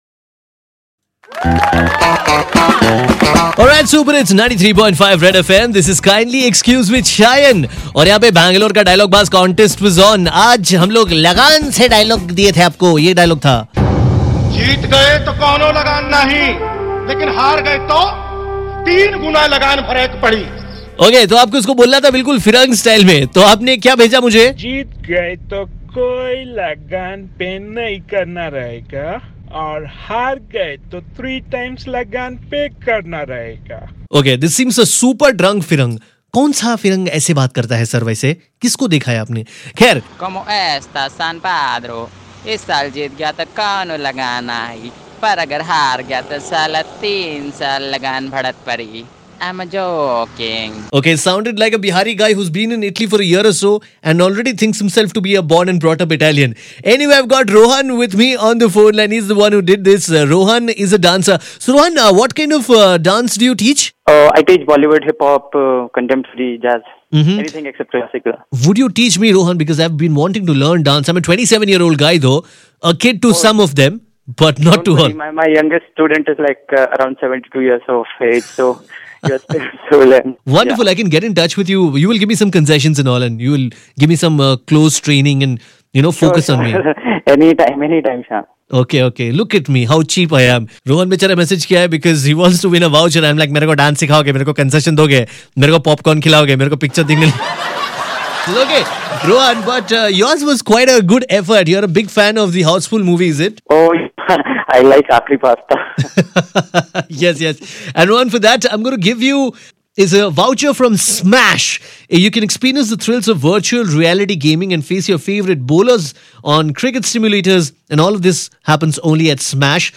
When a pseudo Italian tries a rustic dialogue from Lagaan, this is what happens...